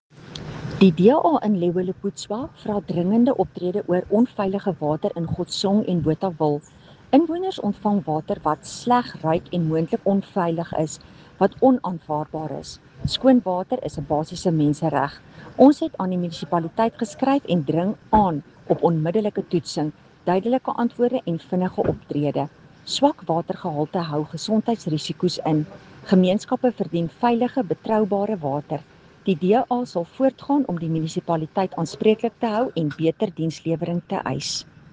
Afrikaans soundbite by Cllr Estelle Pretorius.